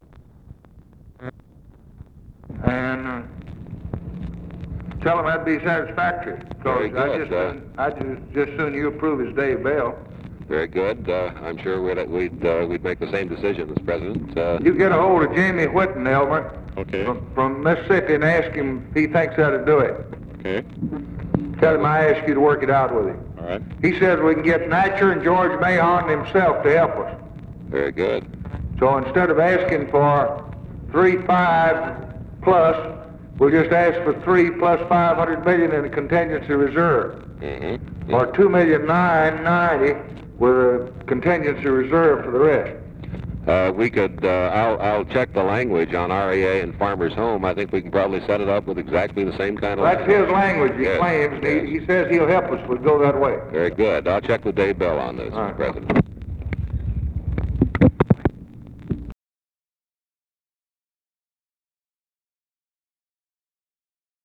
Conversation with KERMIT GORDON and ELMER STAATS, May 27, 1964
Secret White House Tapes